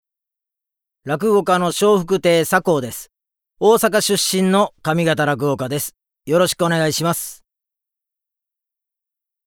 ボイスサンプル ＜ご挨拶＞